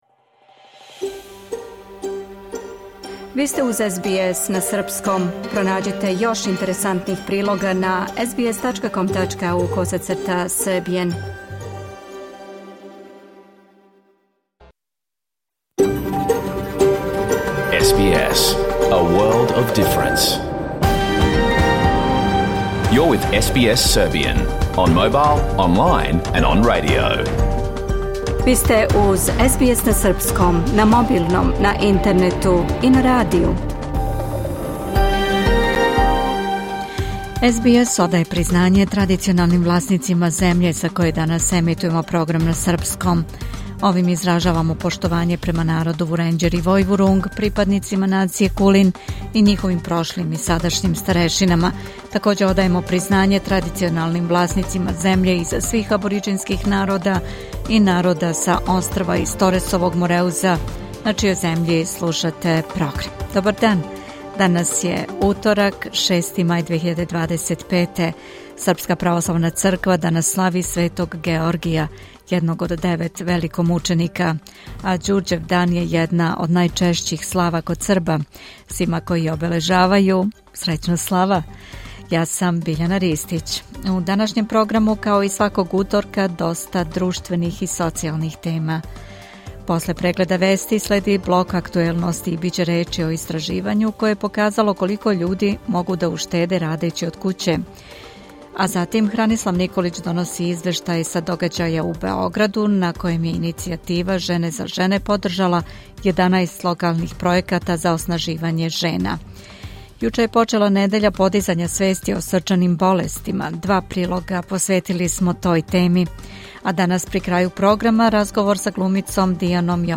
Програм емитован уживо 6. маја 2025. године
Уколико сте пропустили данашњу емисију, можете је послушати у целини као подкаст, без реклама.